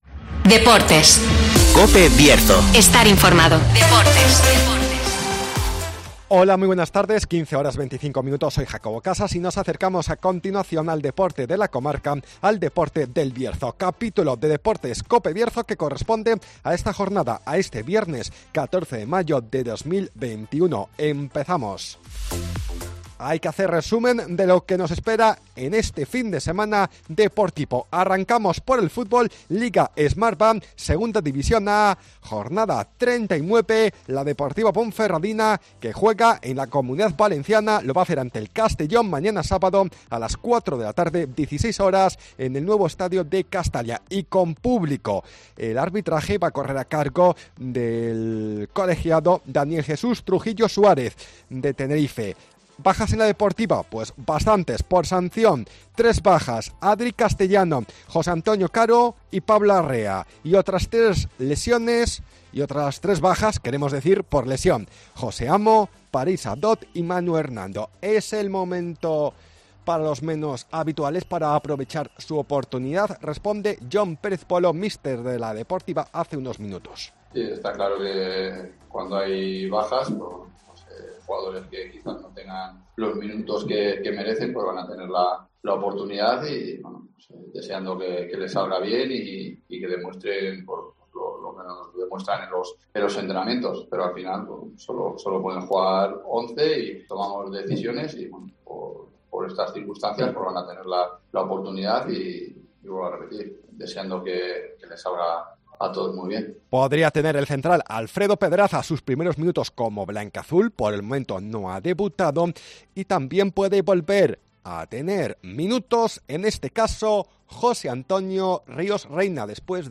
Previa del Castellón - Ponferradina con declaraciones de Jon Pérez Bolo. Además, otros apuntes del fin de semana deportivo.